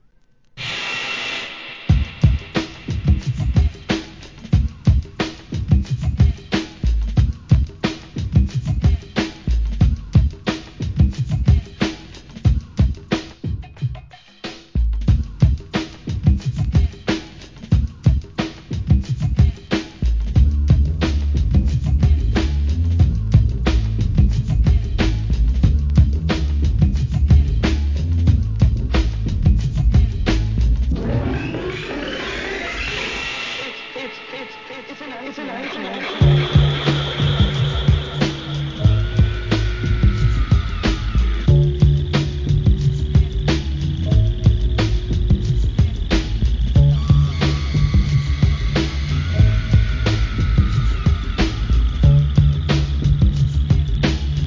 センスが光るサンプリング!!!